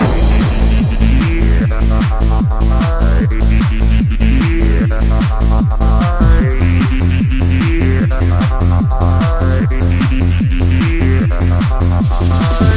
alarm3.wav